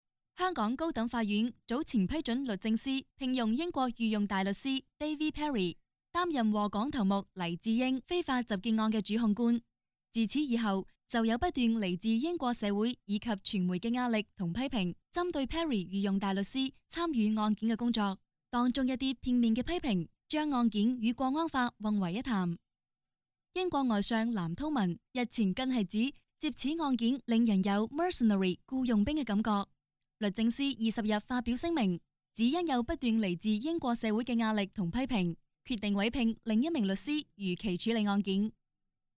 • 1广粤女声1-1
【粤语】新闻播报